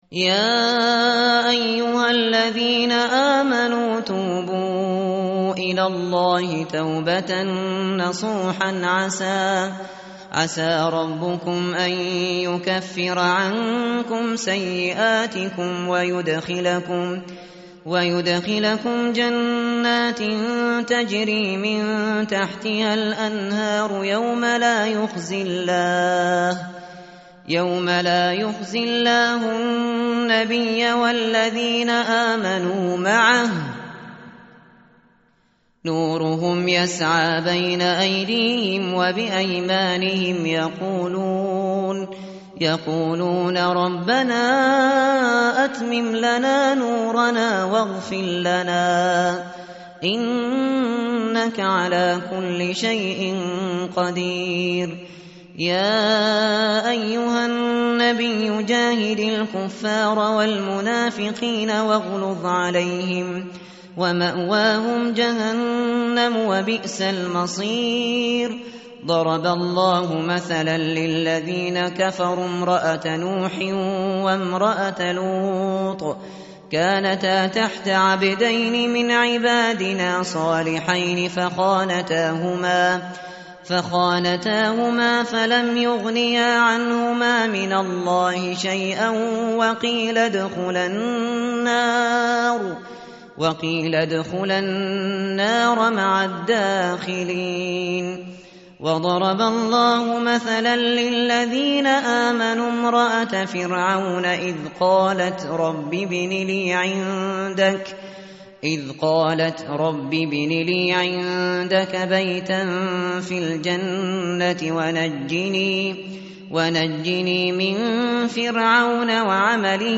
متن قرآن همراه باتلاوت قرآن و ترجمه
tartil_shateri_page_561.mp3